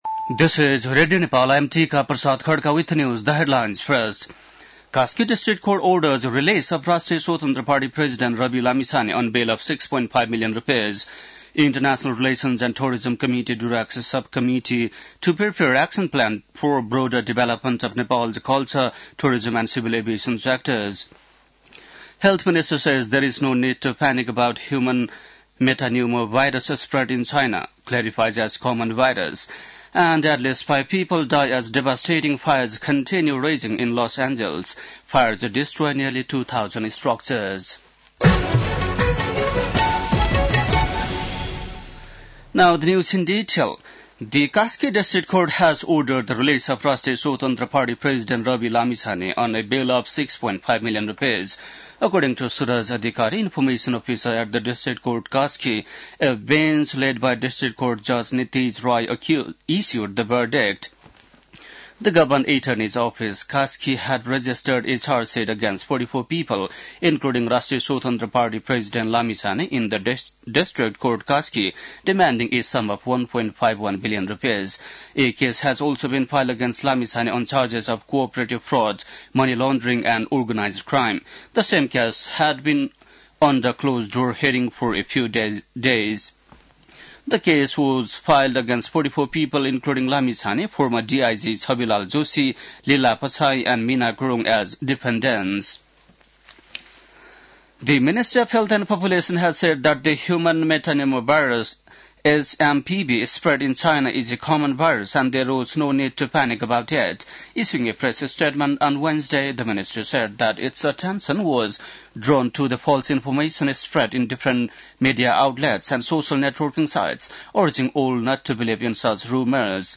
बेलुकी ८ बजेको अङ्ग्रेजी समाचार : २६ पुष , २०८१
8-pm-english-news-9-25.mp3